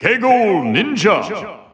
The announcer saying Greninja's name in Korean releases of Super Smash Bros. 4 and Super Smash Bros. Ultimate.
Greninja_Korean_Announcer_SSB4-SSBU.wav